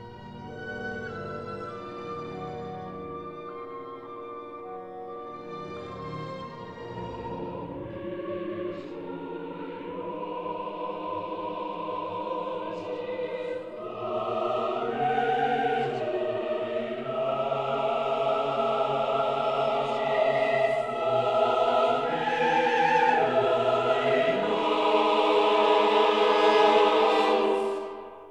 soprano
alto
tenor
bass
organ
Stereo recording made in Dvořák Hall, Prague 22- 24 May 1961